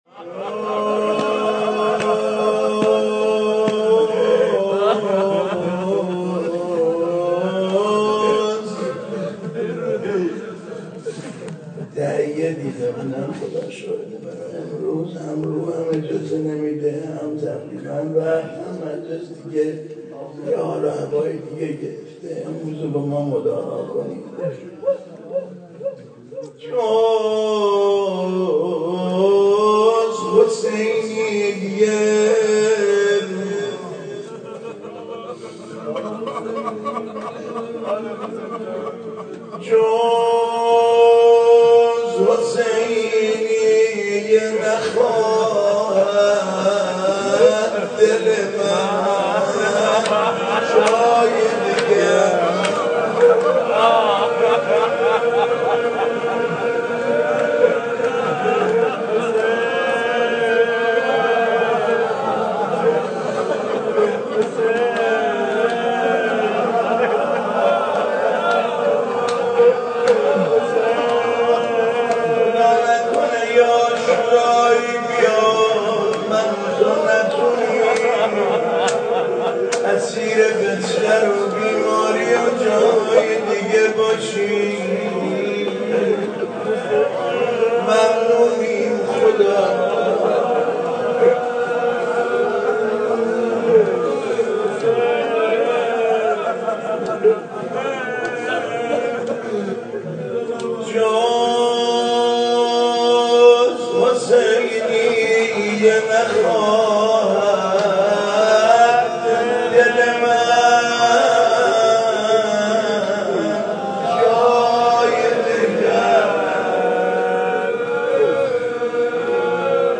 مداحی حاج علي انساني روز پنجم محرم 94، در حسينيه صنف لباس فروشان